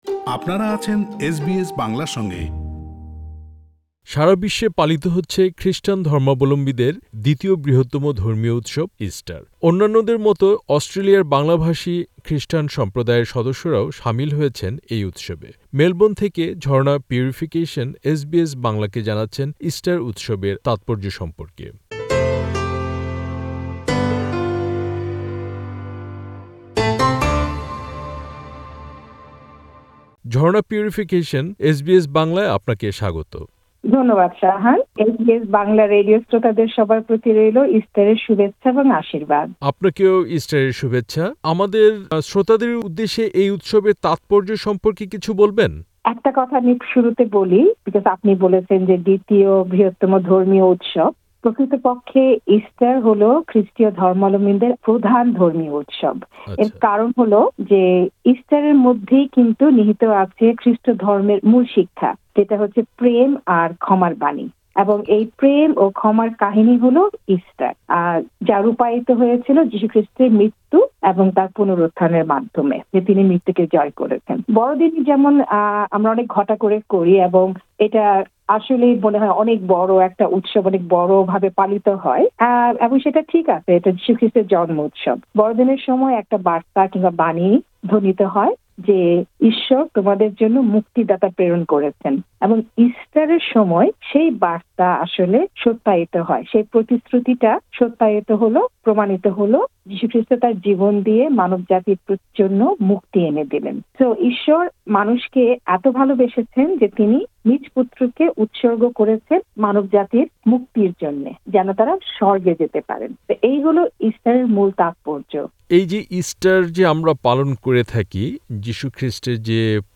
পুরো সাক্ষাৎকারটি